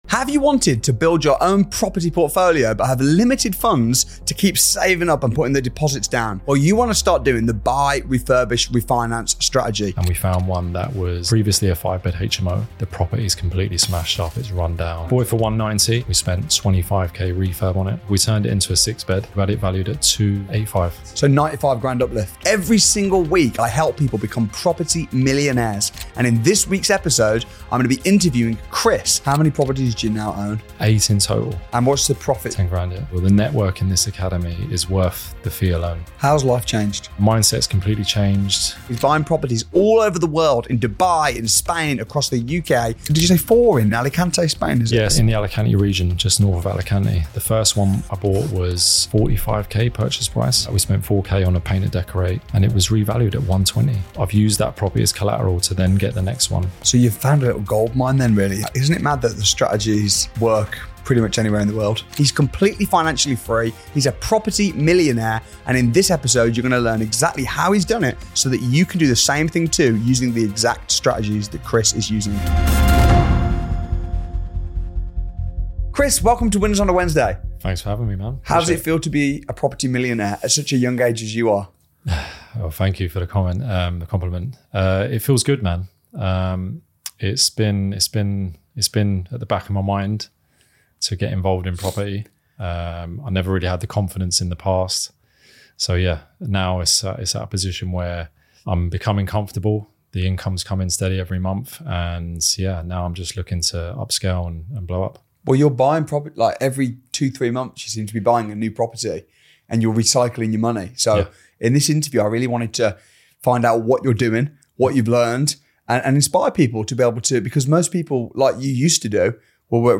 In this interview, we break down his deals, strategies, and mindset so YOU can learn how to replicate his success!